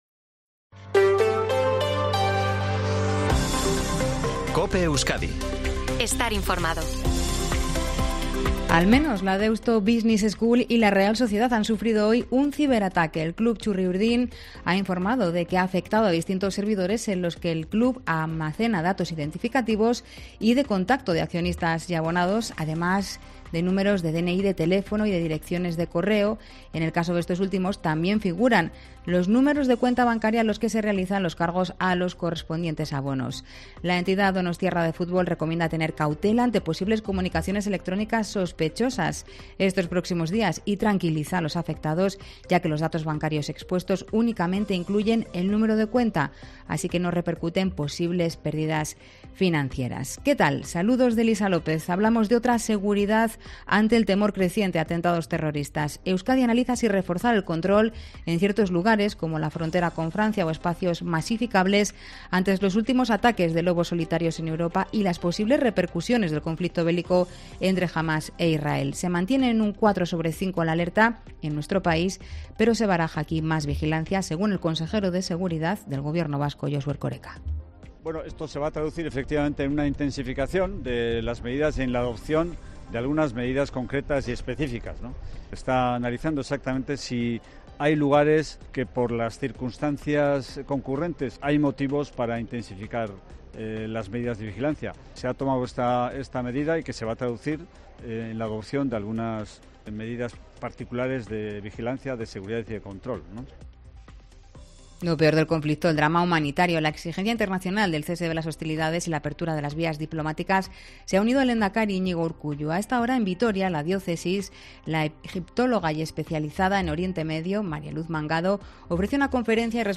INFORMATIVO TARDE COPE EUSKADI 18/10/2023